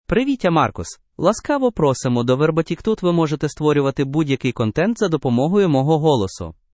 Marcus — Male Ukrainian AI voice
Marcus is a male AI voice for Ukrainian (Ukraine).
Voice sample
Listen to Marcus's male Ukrainian voice.
Marcus delivers clear pronunciation with authentic Ukraine Ukrainian intonation, making your content sound professionally produced.